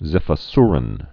(zĭfə-srən)